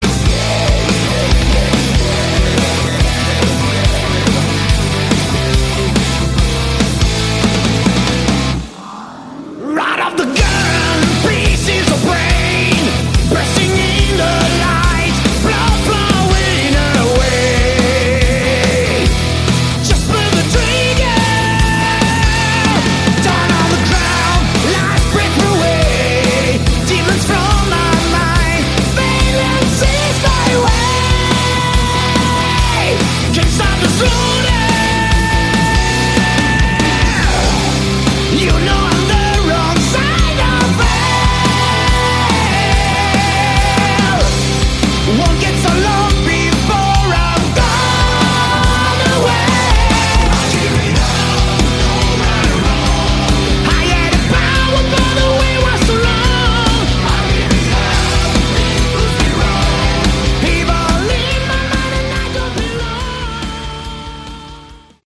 Category: Hard Rock
guitar
keyboards
lead vocals
bass
drums